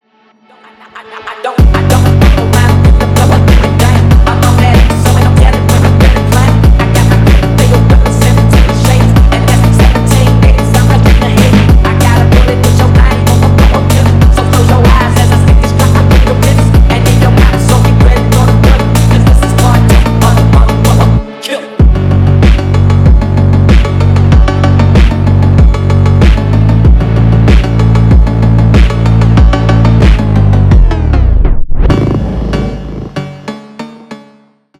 качающие
phonk